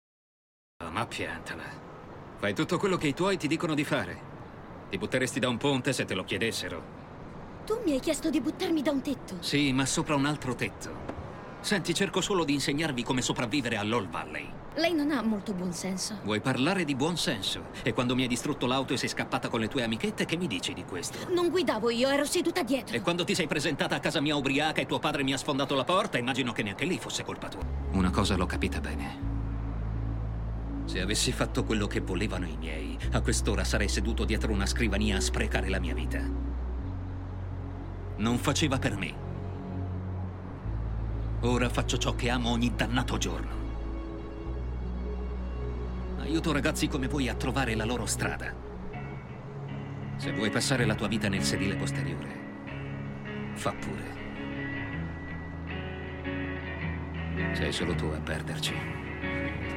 nel telefilm "Cobra Kai", in cui doppia William Zabka.